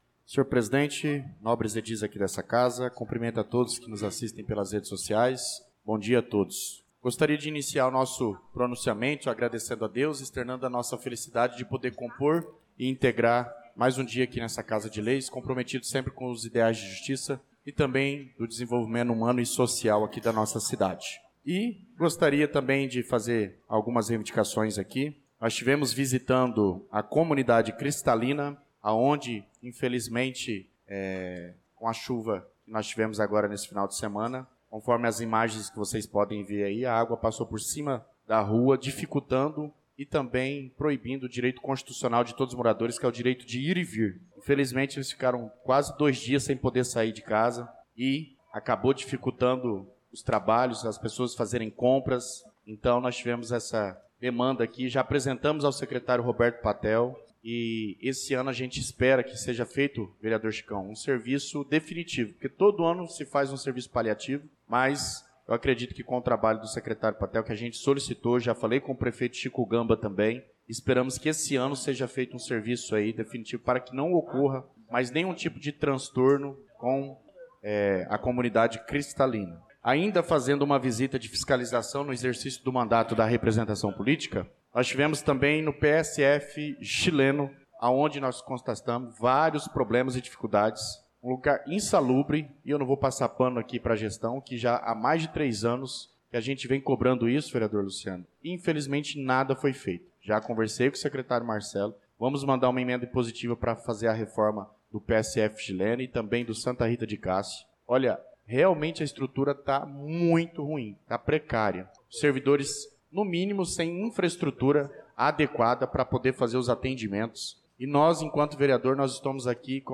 Pronunciamento do vereador Douglas Teixeira na Sessão Ordinária do dia 11/03/2025